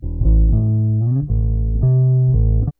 BASS 10.wav